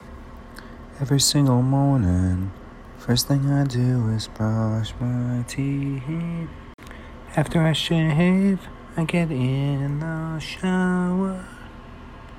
intro I think G